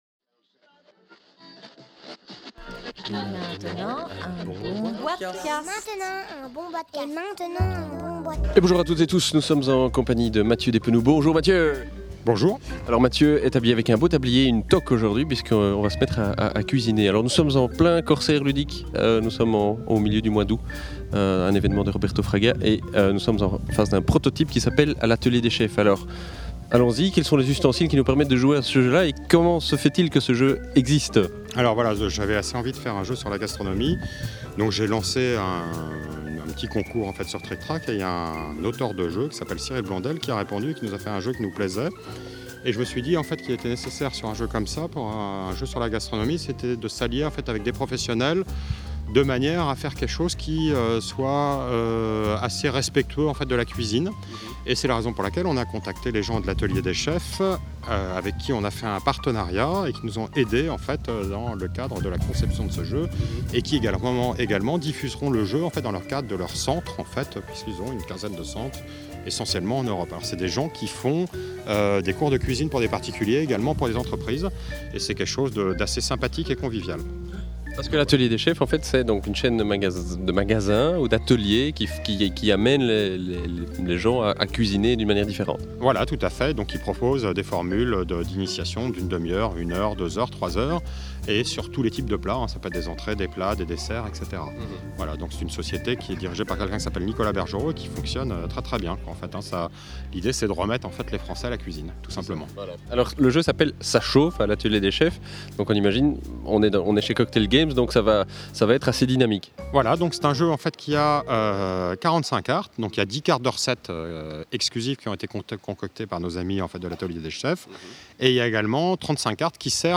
(enregistré le 22 août 2010 lors des Rencontres du Corsaire Ludique)